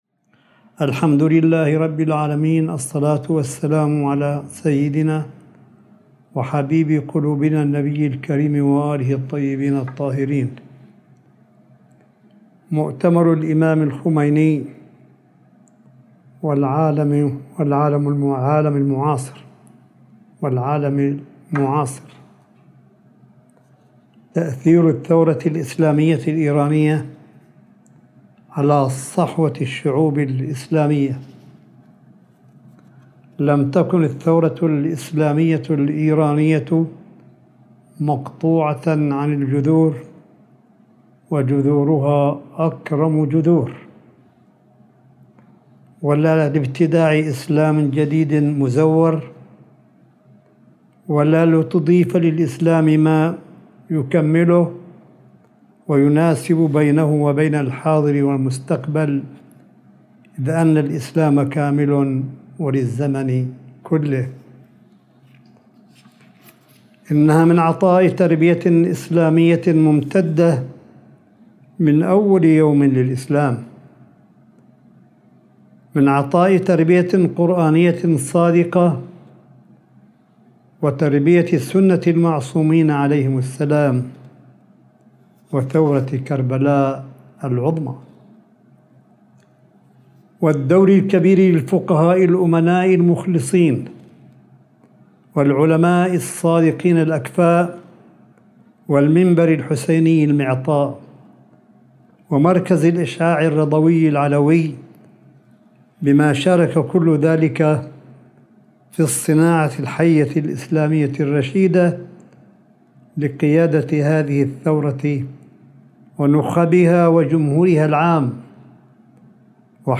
ملف صوتي لكلمة آية الله قاسم في الذكرى الثانية والثلاثون لرحيل الامام الخميني (قدّس سره) – 03 يونيو 2021